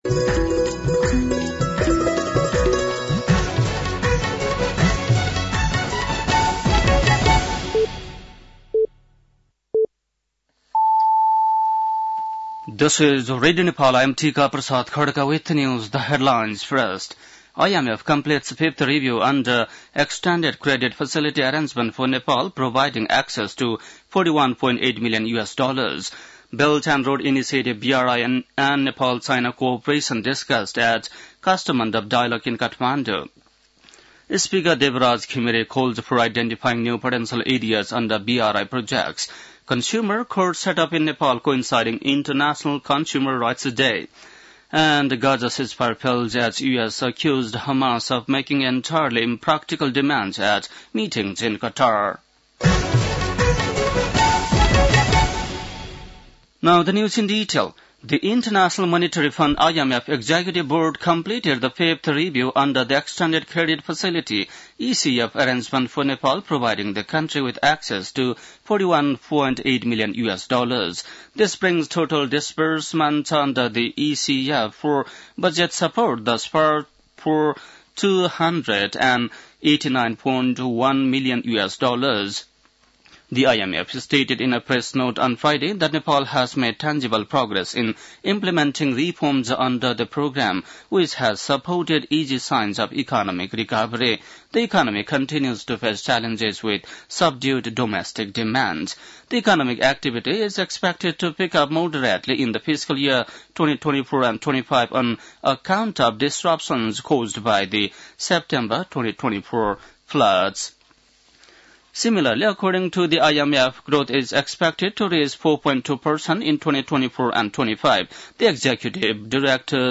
बेलुकी ८ बजेको अङ्ग्रेजी समाचार : २ चैत , २०८१